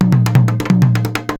PERC 15.AI.wav